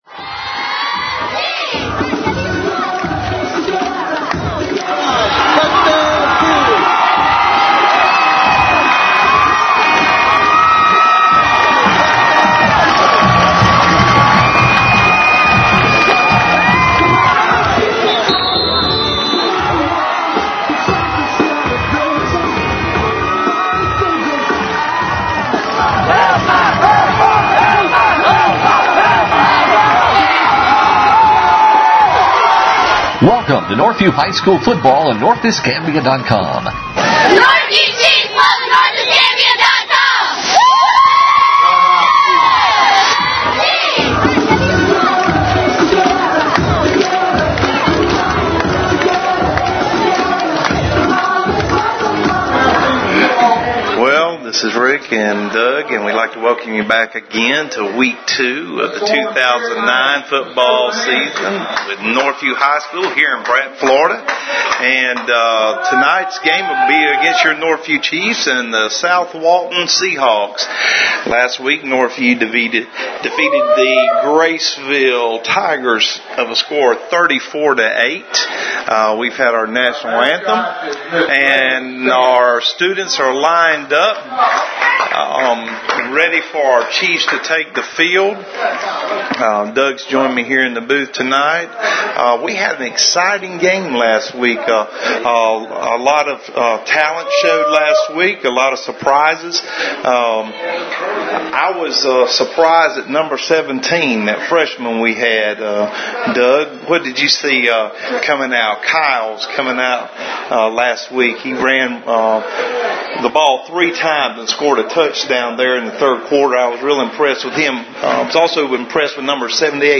NHS-southwaltonbroadcast.mp3